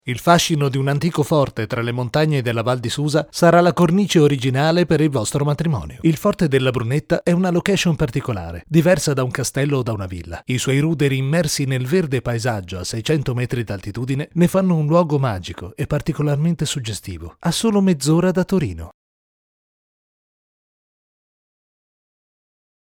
Sprechprobe: eLearning (Muttersprache):
I try to be an "any purpose" voice.